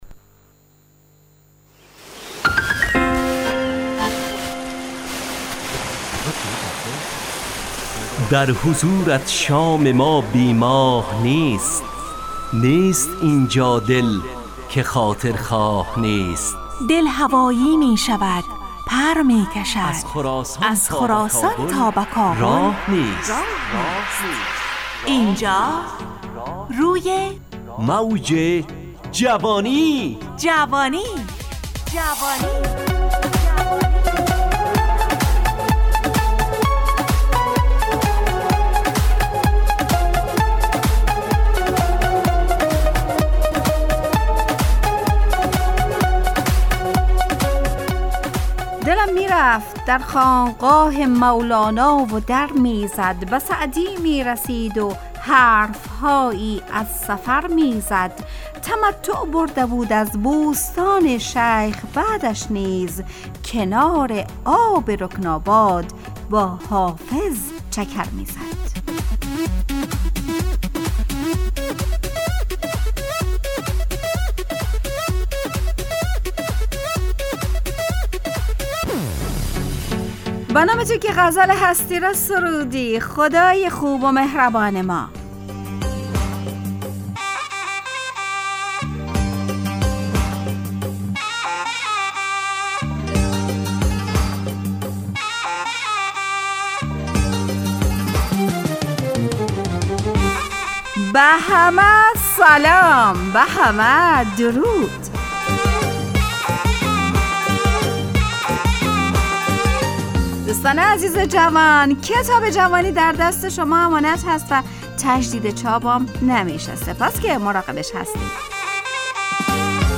روی موج جوانی 1403/3/8/برنامه شادو عصرانه رادیودری.
همراه با ترانه و موسیقی مدت برنامه 70 دقیقه .